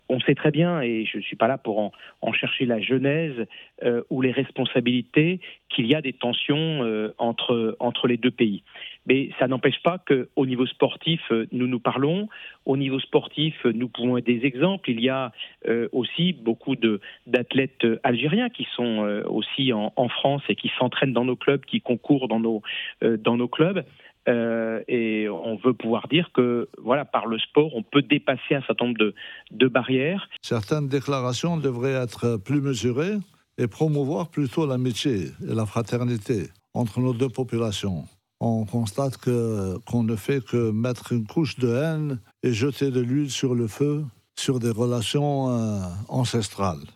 Dans un entretien exclusif accordé à nos confrères d’Africa Radio, les présidents du CNOSF, le Français David Lappartient, et de l’ACNOA (association des Comité nationaux olympiques africains), l’Algérien Mustapha Berraf, ont pris tour à tour la parole pour appeler à l’apaisement des relations entre la France et l’Algérie.